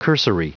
Prononciation du mot cursory en anglais (fichier audio)
Prononciation du mot : cursory